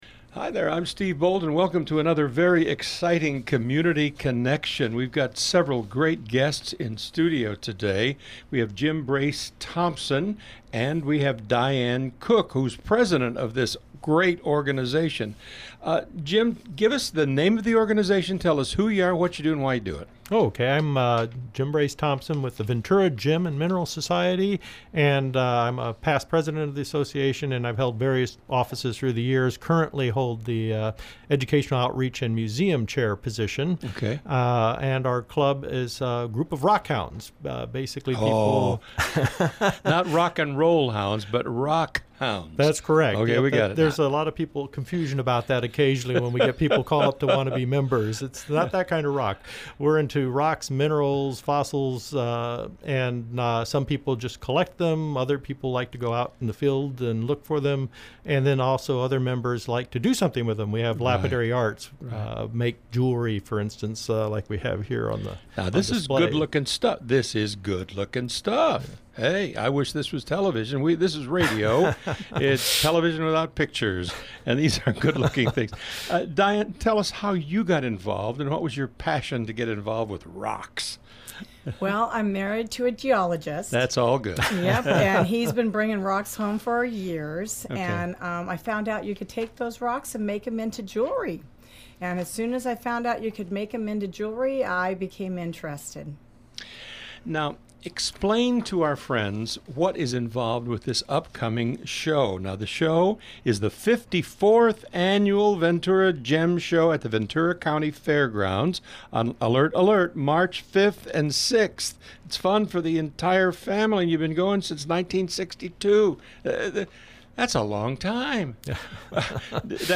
VGMS on the Radio!
VGMSRadioBroadcast2016.mp3